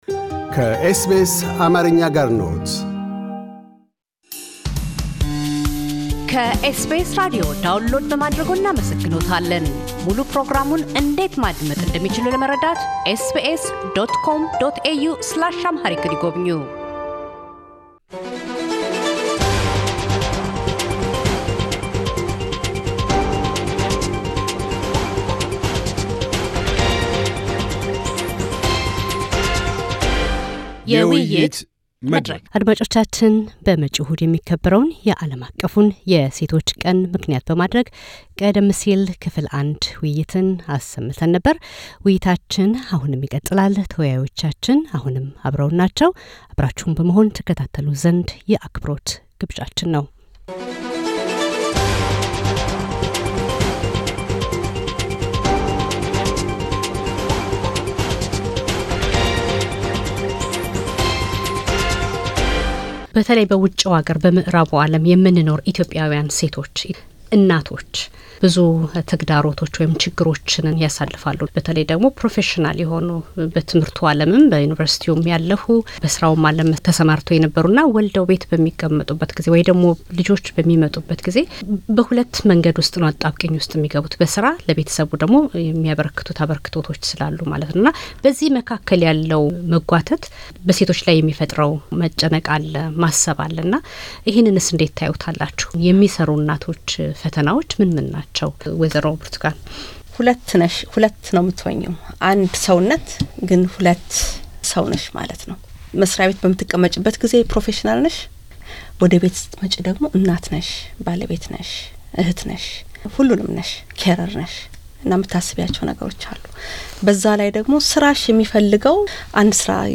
የውይይት መድረክ “ የአለም አቀፍ ሴቶች ቀን ማርች 8” - ክፍል ሁለት
በአጠቃላይ በማህበረሰቡ ዘንድ ስር ሰድዶ የተንሰራፋውን ሴቶችን የማሳነስ ፤አይችሉም የማለት፤ ሳይመዘኑ መፈረጅ የመሳሰሉትን ጎታች አስተሳሰቦች ለማስወገድ ሁሉም በጋር መስራት እንዳለበት የውይይታችን ተሳታፊዎች ያምናሉ።